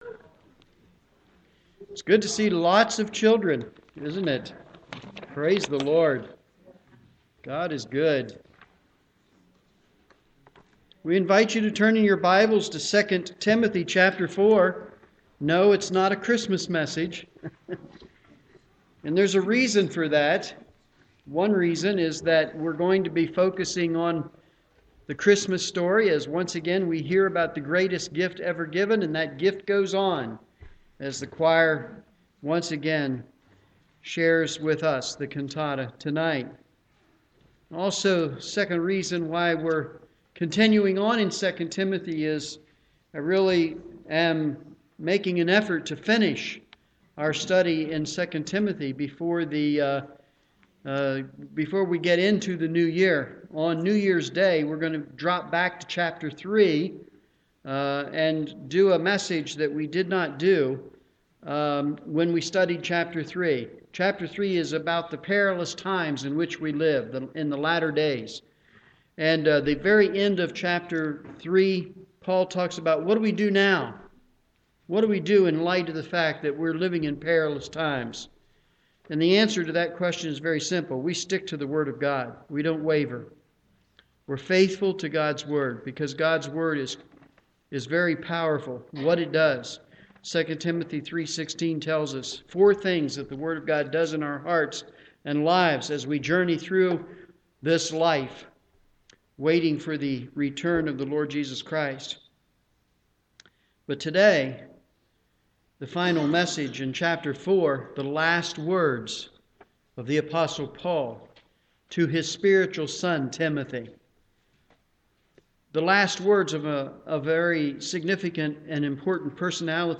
Morning Service
Sermon